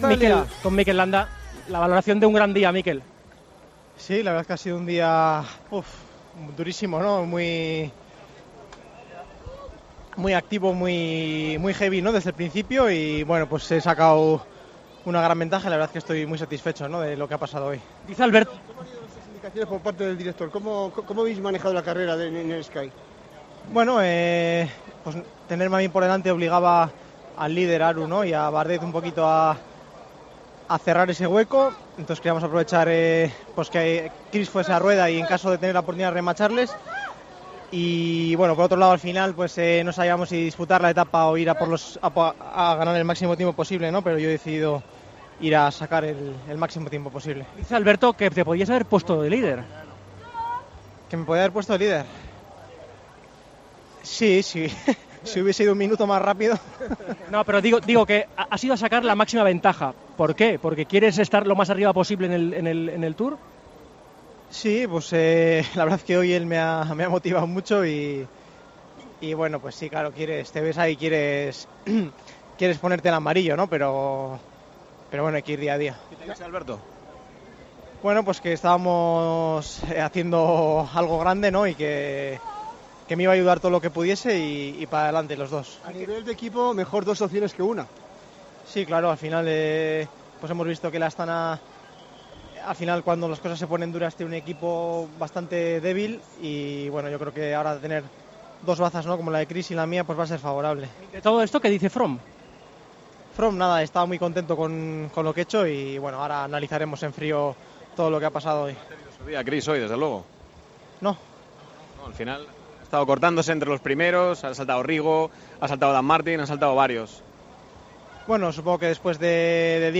El ciclista del Sky afirmó a su llegada a meta que Chris Froome sigue siendo el líder del equipo y que había atacado para que el británico "pudiera rematar". Además, explicó que tiene muy claro su rol de gregario y que Contador "me ha dicho que me iba a ayudar y que tirásemos a muerte".